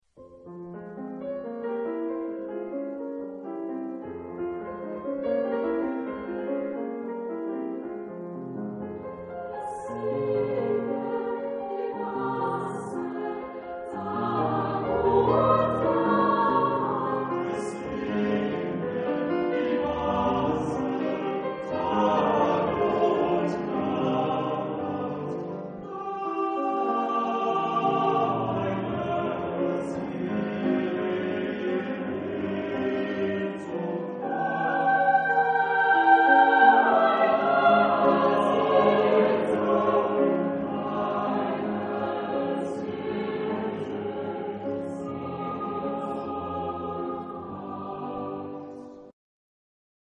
Genre-Style-Forme : Pièce chorale ; Cycle ; Profane
Type de choeur : SATB  (4 voix mixtes )
Instruments : Piano (1)
Tonalité : fa mineur